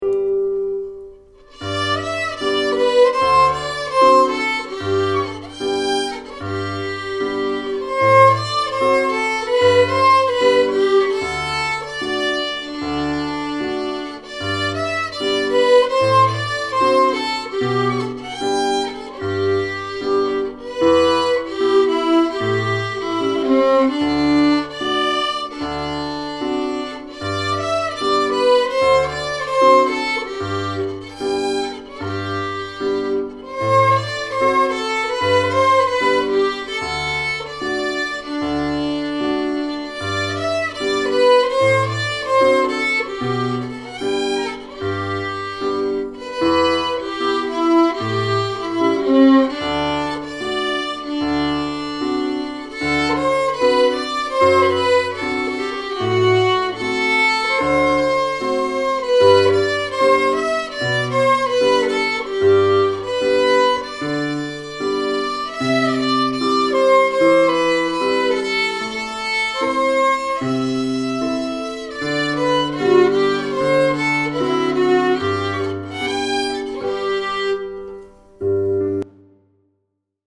Compared to a glasser NY, plastic (no idea of price, borrowing it from friend) Apologies for bad playing, it's the level I'm at.
I can hear the Glasser bow and both, but not the FM bow by itself.
Yes, apologies, have absolutely no idea how I got both bows on the same track, bit of a worthless post I'm afraid, I didn't know how to fix it - I don't play well enough to do the bow justice for sure.